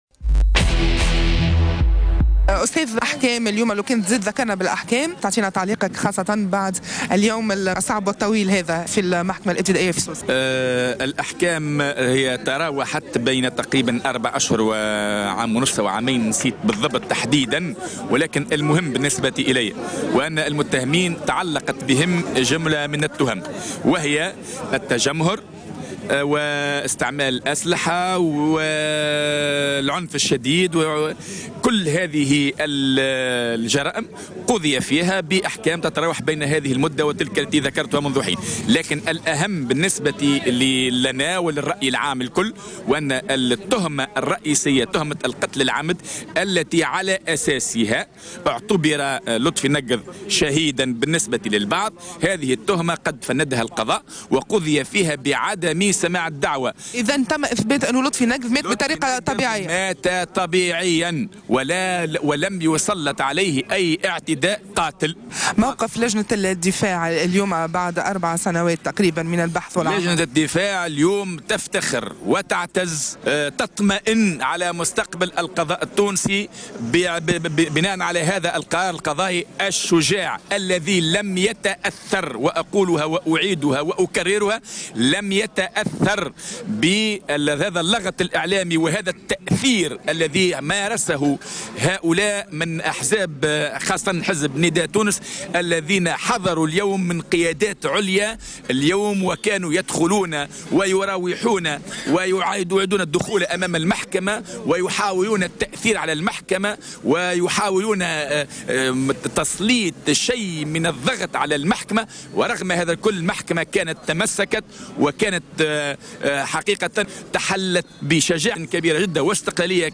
وأكدت في اتصال هاتفي مع "الجوهرة أف أم" أن جماعة نداء تونس أتوا للمحكمة أمس لأول مرة ولم يحضروا في السابق وعلى امتداد السنوات الماضية، مشيرة إلى أنها توجهت لهم باللوم على غيابهم، وذلك في تعليقها على اتهامها بالضغط على القضاء.